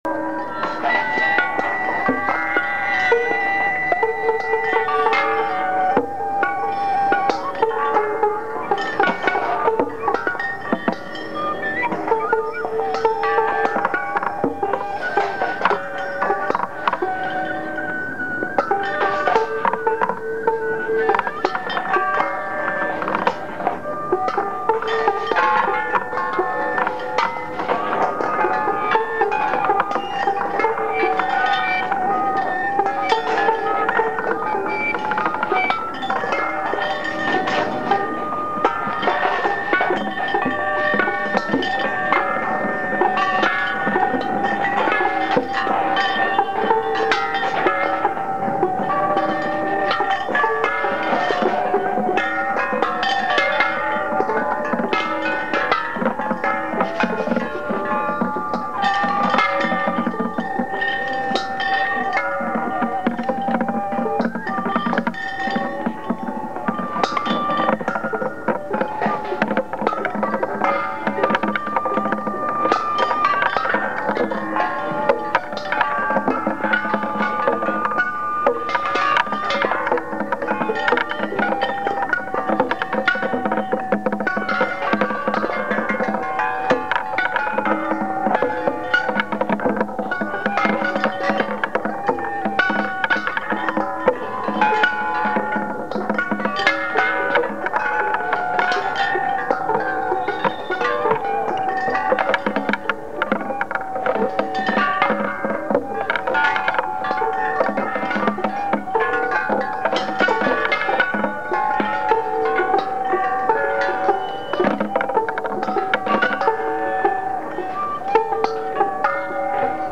Location: Stevens Square/Red Hot Art